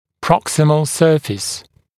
[‘prɔksəməl ‘sɜːfɪs][‘проксэмэл ‘сё:фис]проксимальная поверхность, боковая поверхность зуба